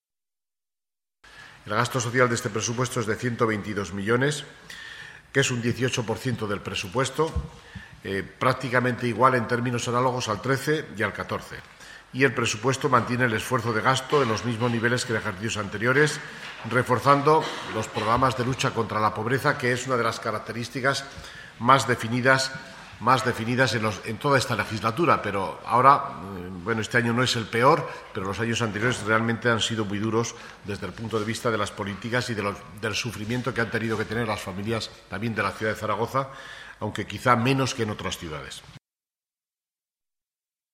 A este respecto, Fernando Gimeno ha dicho lo siguiente: